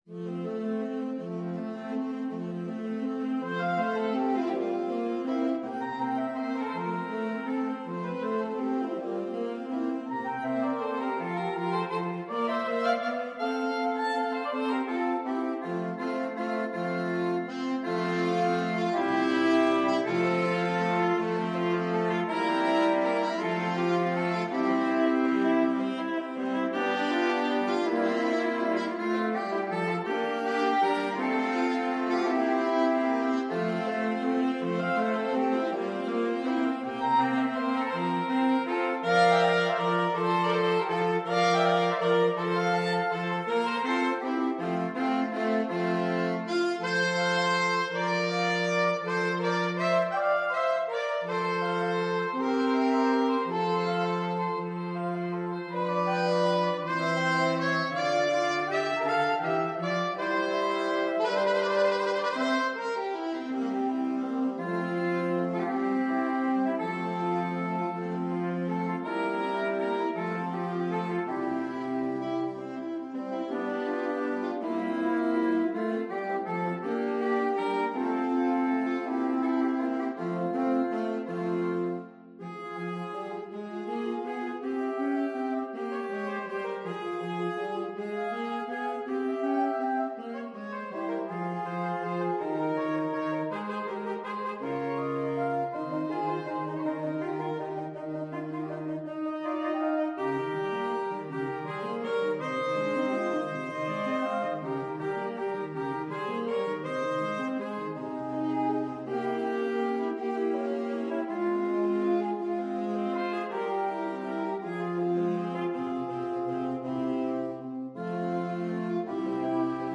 6/8 (View more 6/8 Music)
. = 54 Molto lento
Pop (View more Pop Saxophone Quartet Music)